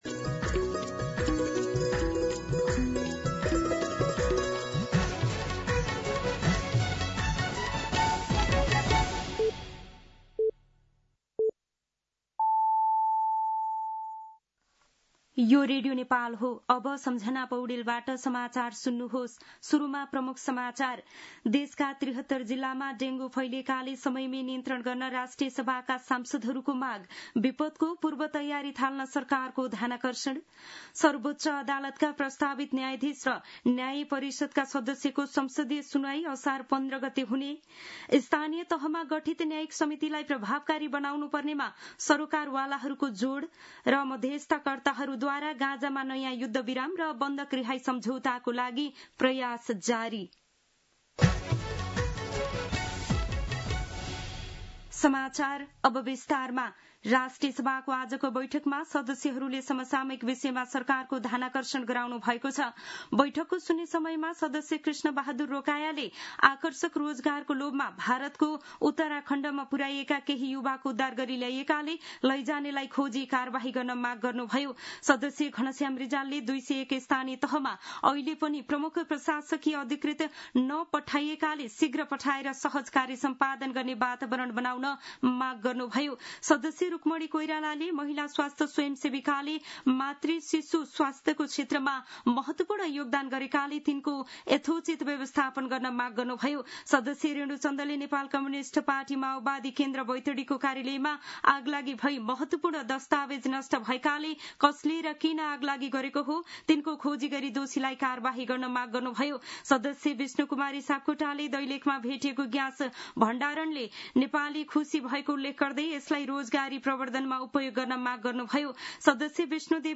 दिउँसो ३ बजेको नेपाली समाचार : १२ असार , २०८२